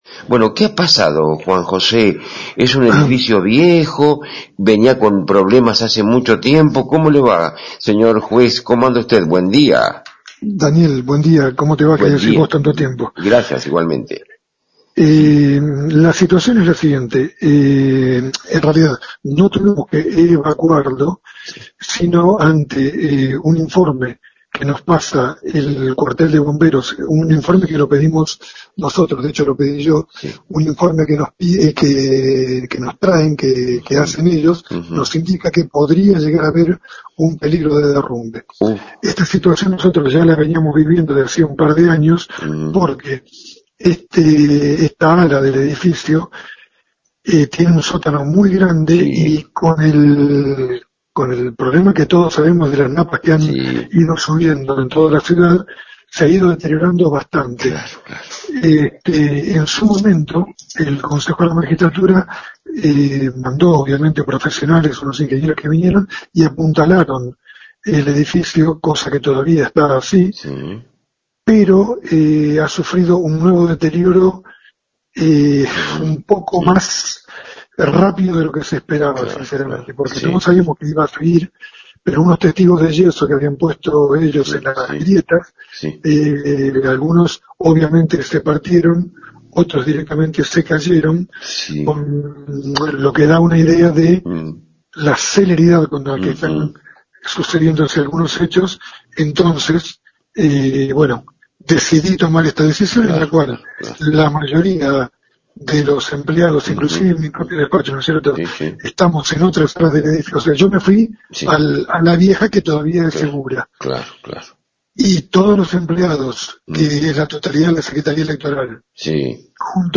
Entrevista al Juez Juan José Baric